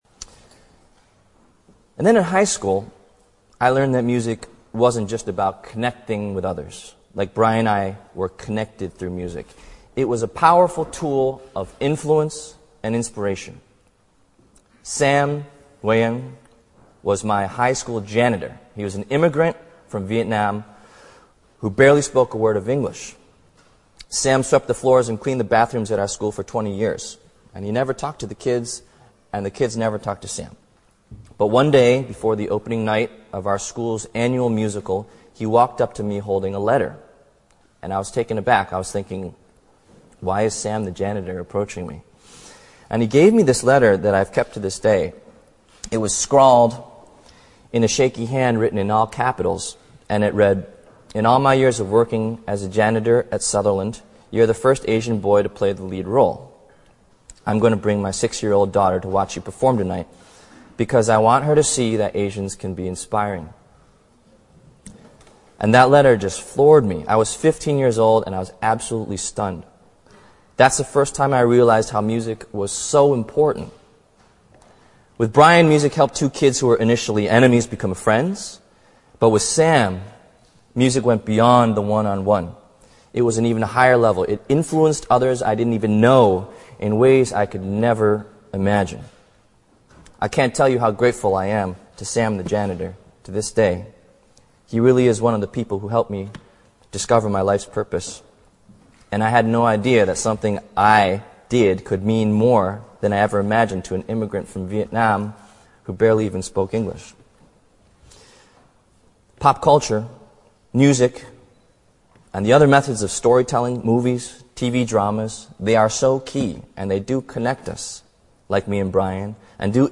在线英语听力室王力宏牛津大学演讲 第7期的听力文件下载,哈佛牛津名人名校演讲包含中英字幕音频MP3文件，里面的英语演讲，发音地道，慷慨激昂，名人的效应就是激励他人努力取得成功。